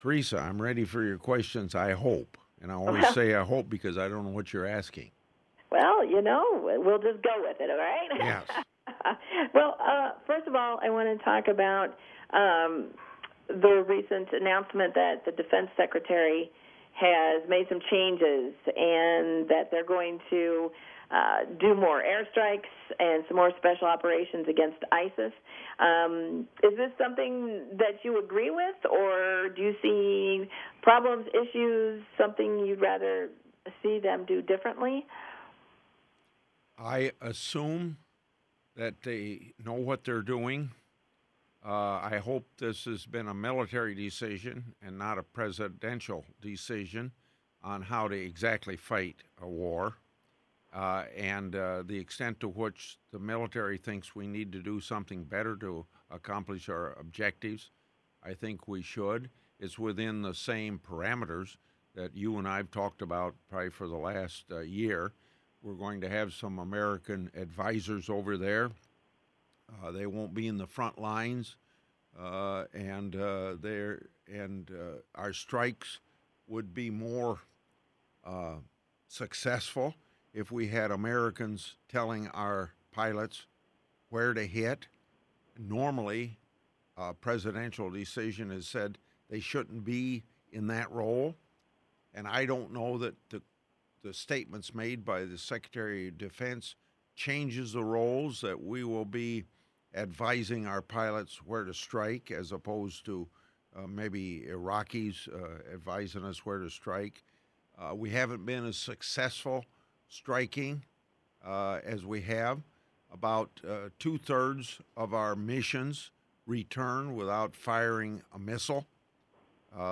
Grassley on KILJ radio